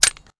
weapon_foley_drop_13.wav